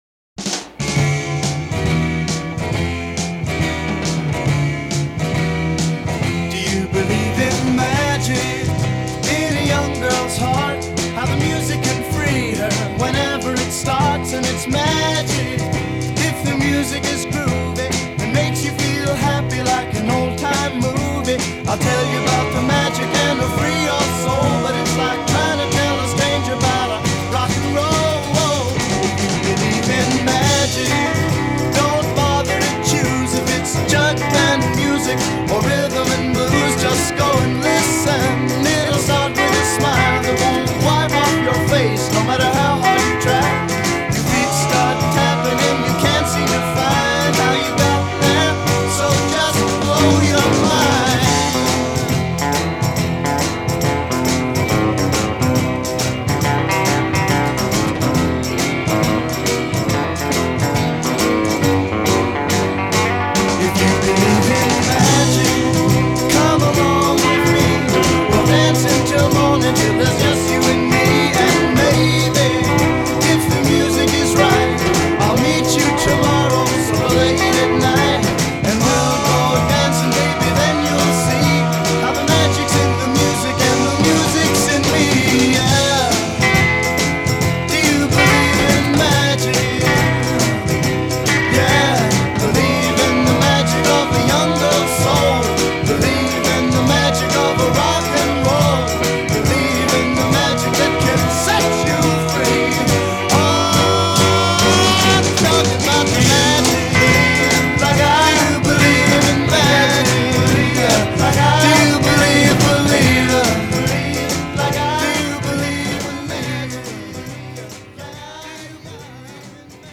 happy, good-time, jug band-y music